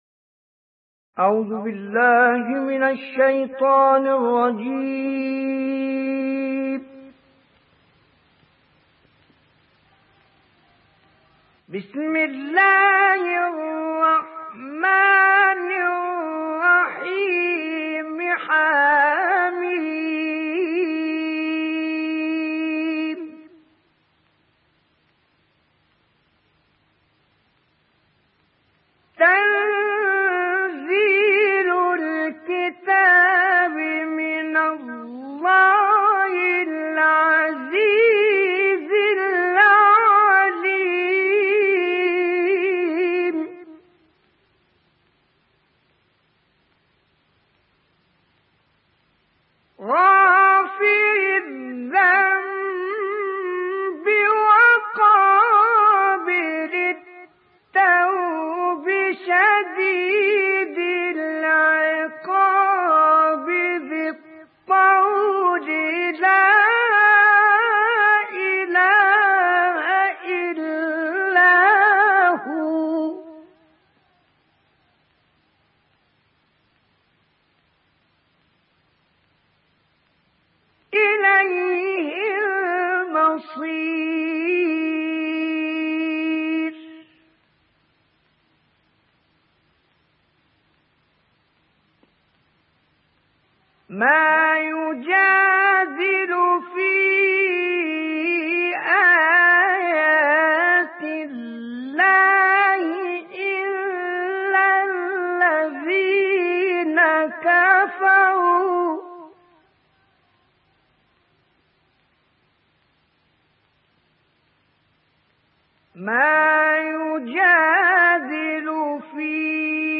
تلاوت کوتاه مجلسی